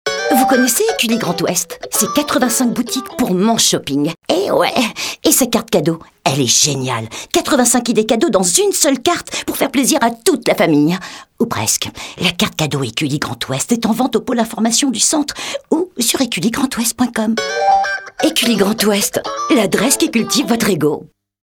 🔊 Spot publicitaire Radio pour un Centre Commercial